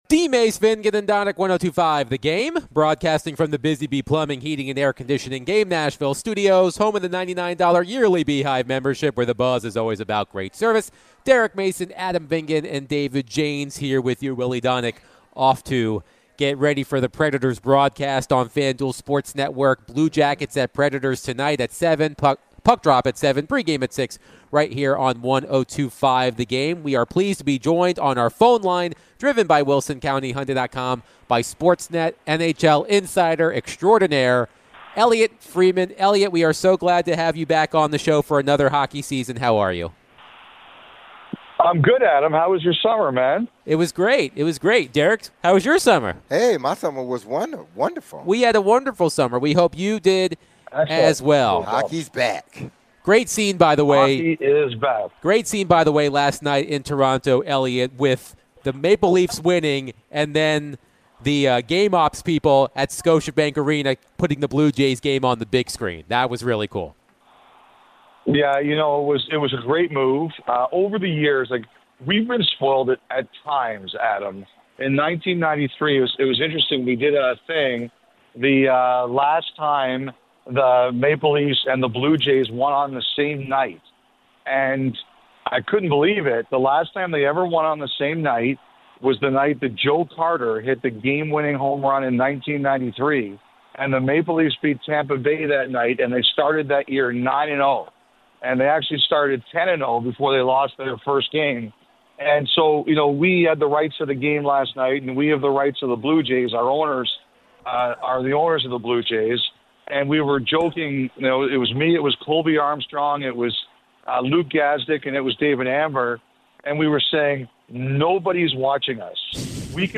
NHL Insider Elliotte Friedman joins DVD to discuss all things Predators and NHL.